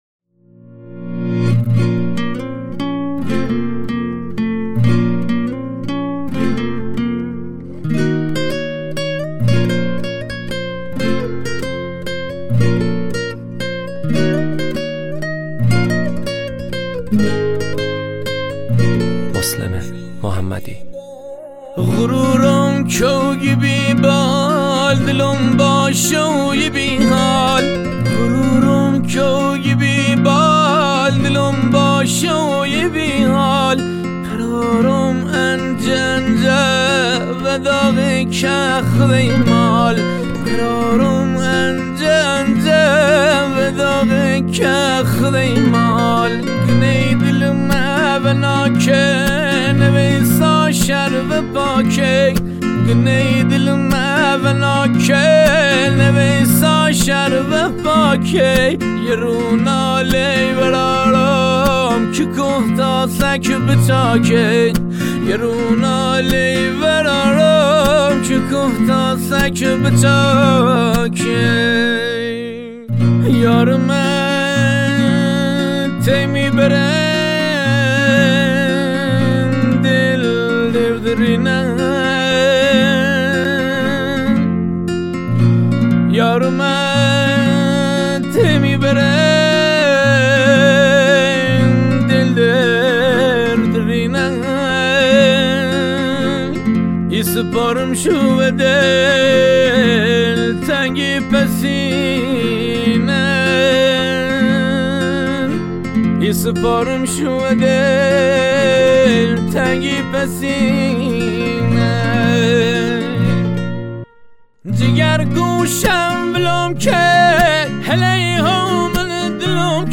دانلود آهنگ لری
خواننده آهنگ